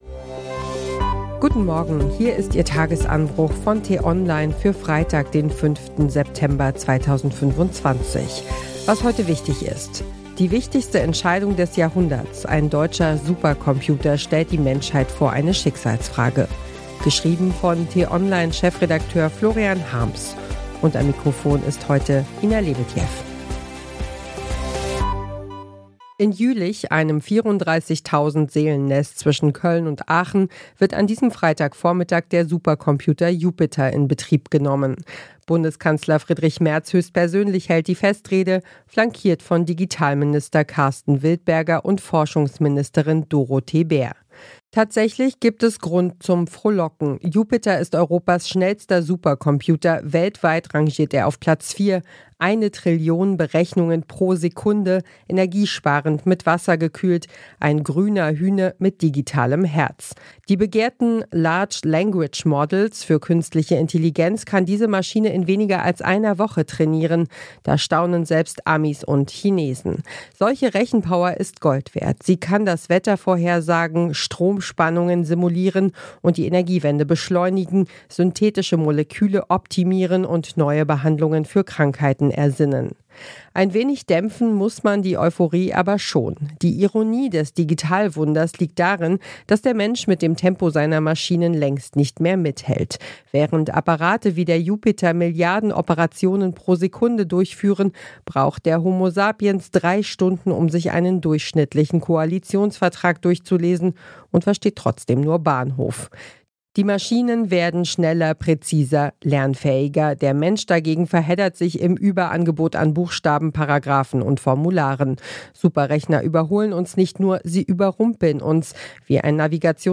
Der Nachrichtenpodcast von t-online zum Start in den Tag.